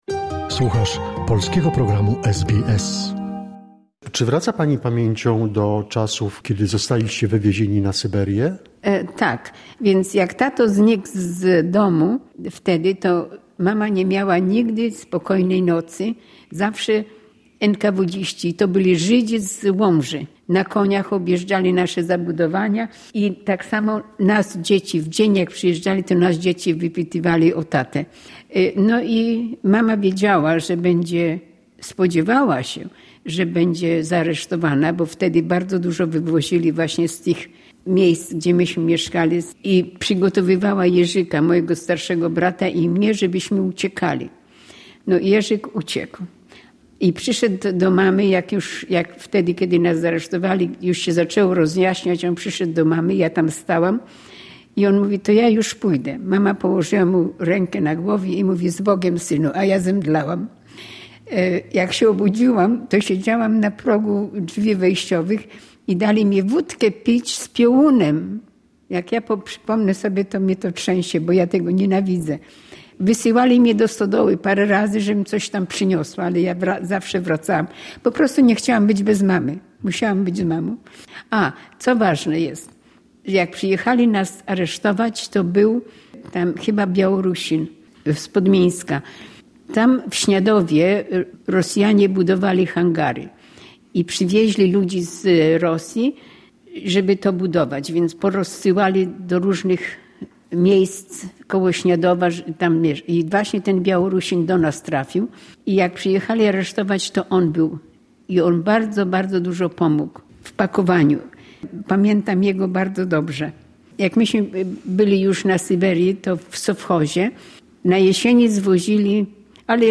Posłuchaj całej godzinnej audycji radiowej tutaj